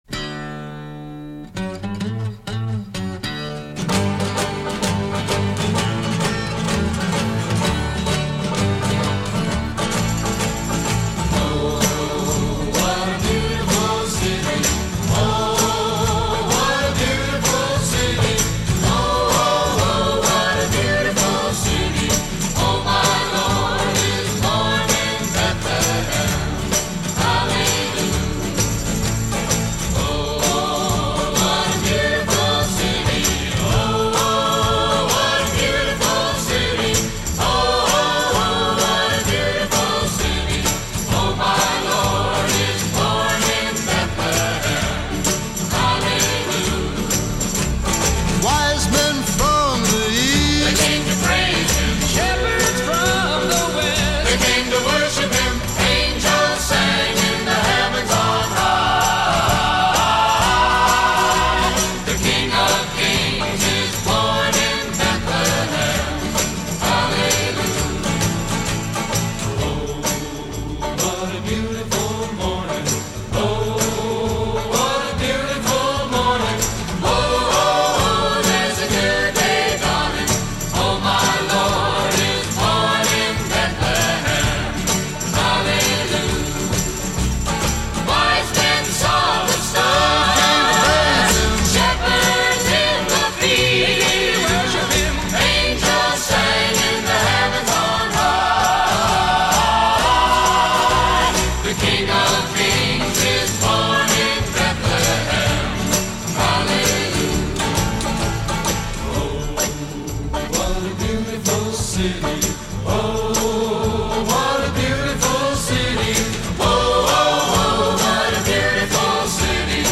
rousing, full-throated ensemble pieces